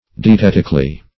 dietetically - definition of dietetically - synonyms, pronunciation, spelling from Free Dictionary Search Result for " dietetically" : The Collaborative International Dictionary of English v.0.48: Dietetically \Di`e*tet"ic*al*ly\, adv. In a dietetical manner.